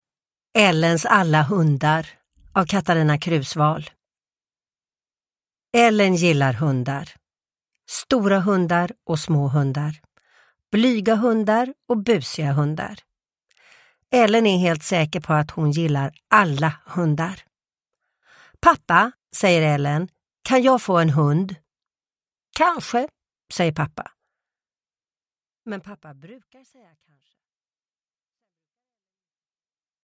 Ellens alla hundar – Ljudbok – Laddas ner
Uppläsare: Sissela Kyle